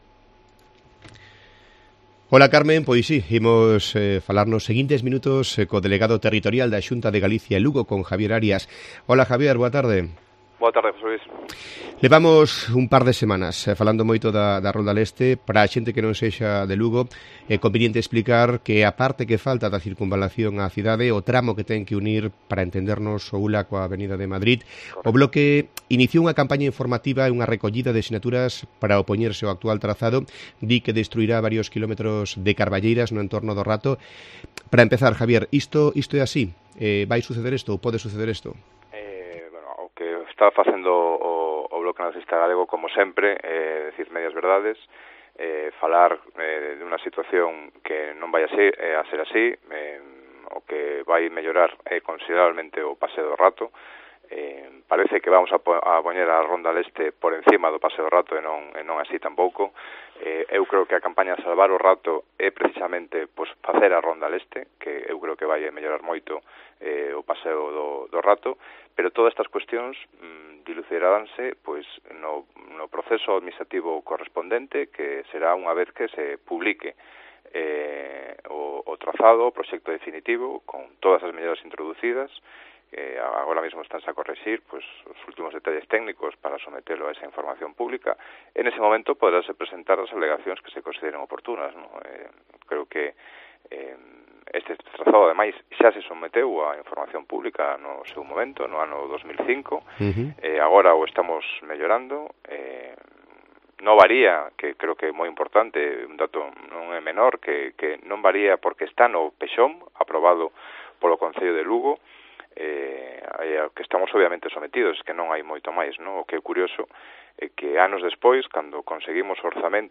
Entrevista al delegado territorial de la Xunta de Galicia en Lugo